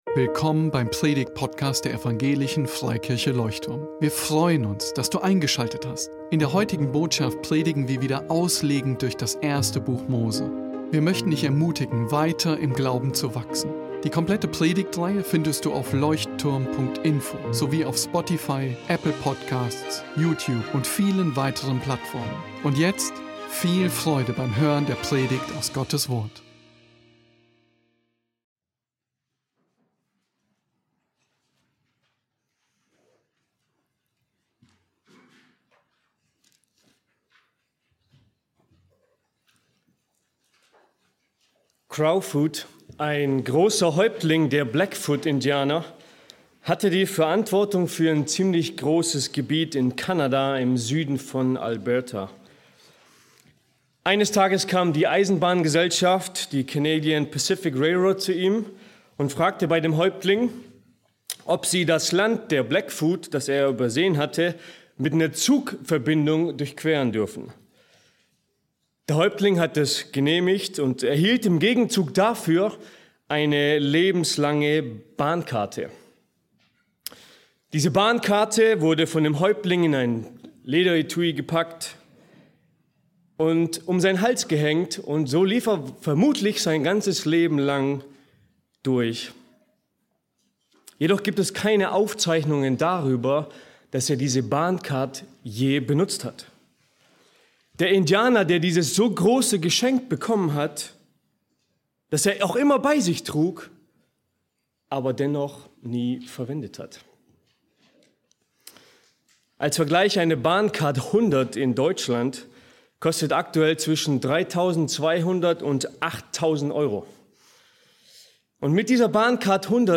Wöchentliche Auslegungspredigt